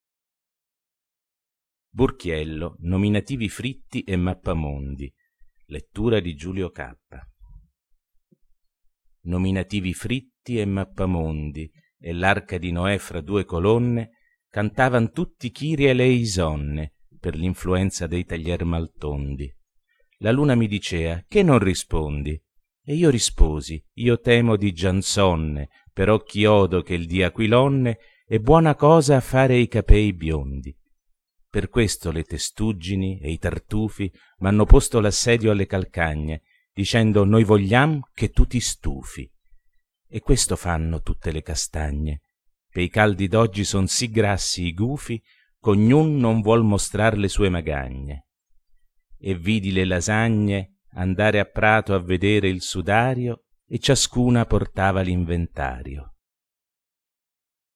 Poesie recitate da artisti